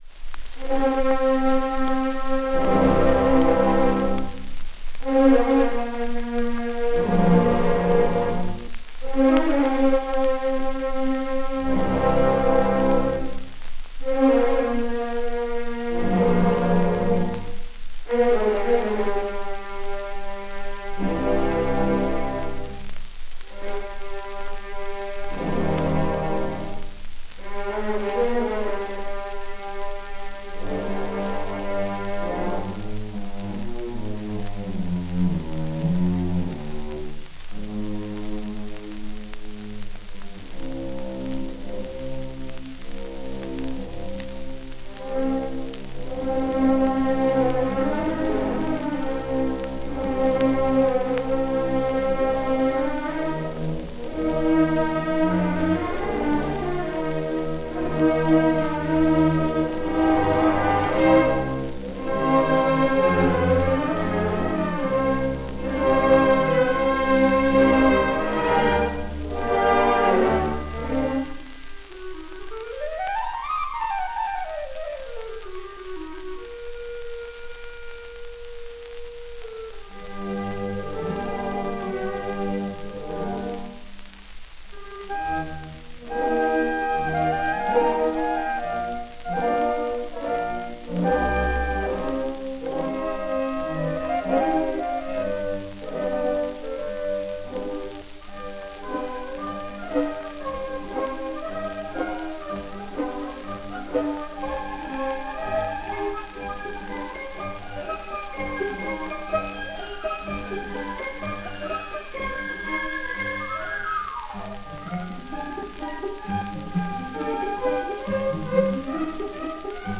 Орк. Моск. ф-и, дир. Александр Орлов - Венгерская рапсодия № 2 (Ференц Лист)
ork.-mosk.-f-i,-dir.-aleksandr-orlov---vengerskaya-rapsodiya-№-2-(ferents-list).mp3